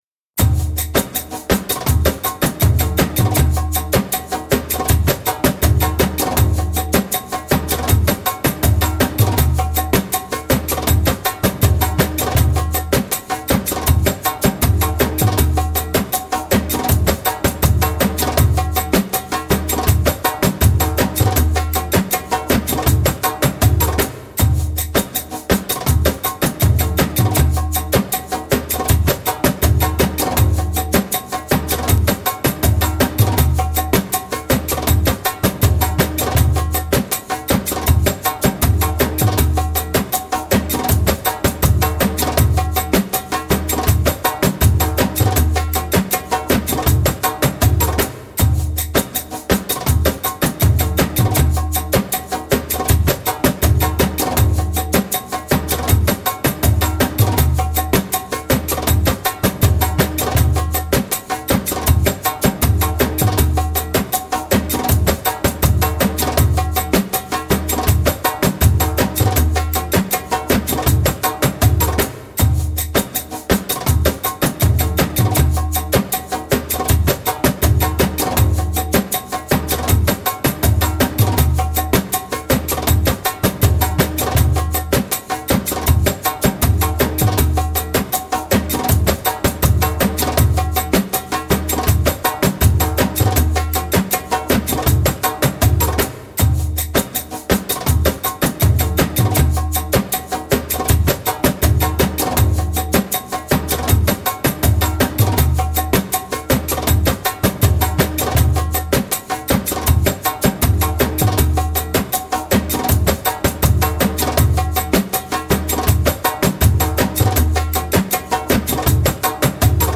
Passionate eastern percussion.
Tagged as: World, Loops, Arabic influenced